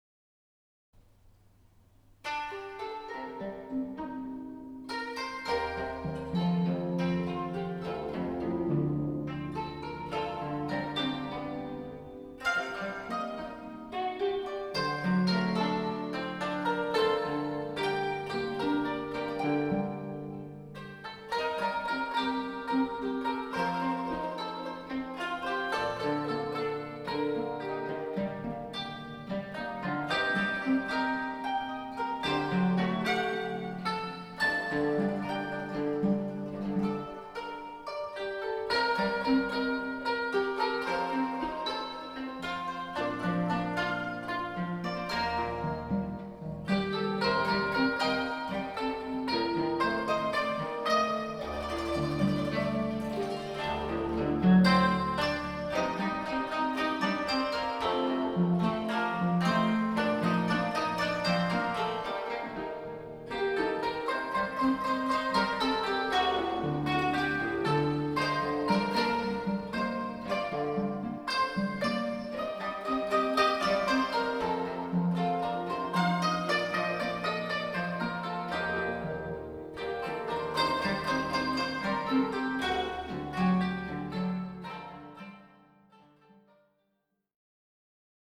箏1
箏2
十七絃
尺八